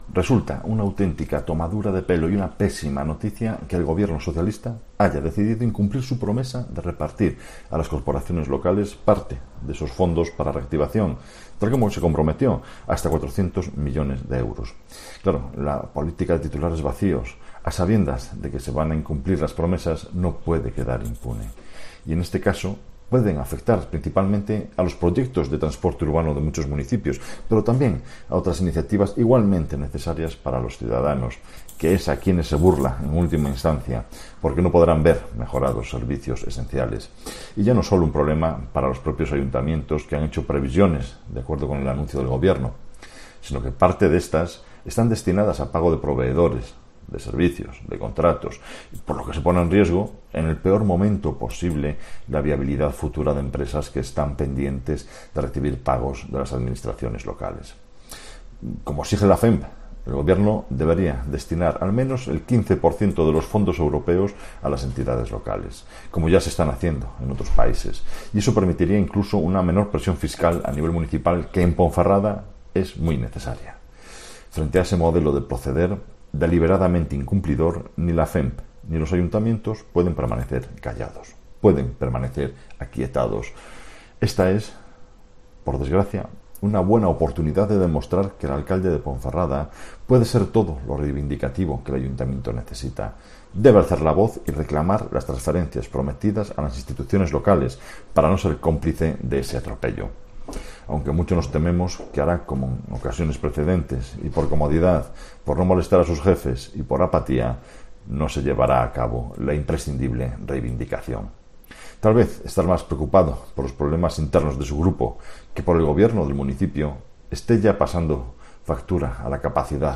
Escucha aquí las palabras del portavoz de los populares en la capital berciana, Marco Morala